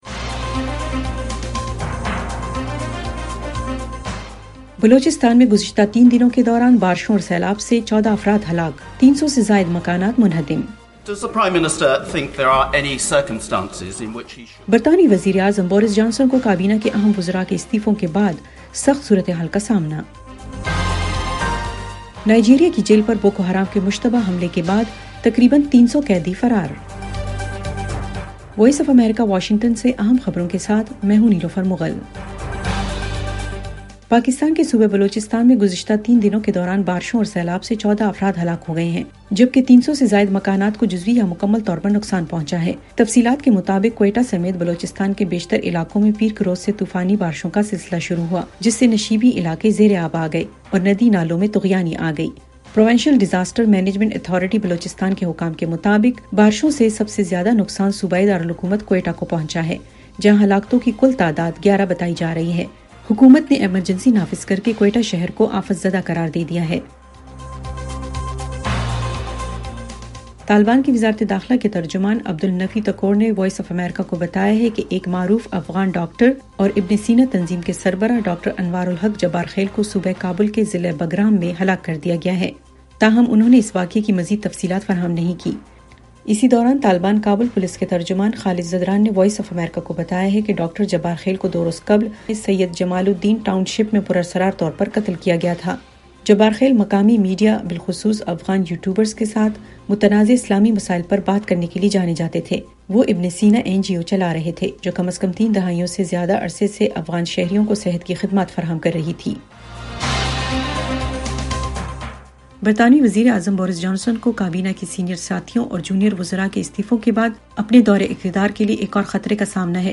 ایف ایم ریڈیو نیوز بلیٹن، رات 10 بجے